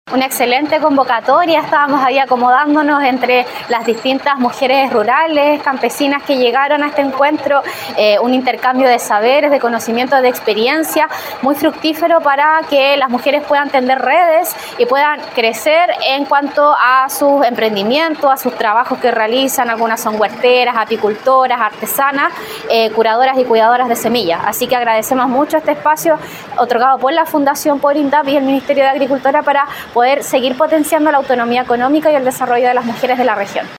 Al respecto, Camila Contreras, seremi de la Mujer y Equidad de Género, valoró la jornada, ya que “este encuentro es un intercambio de saberes, de conocimientos, de experiencias, muy fructífero para tender redes”.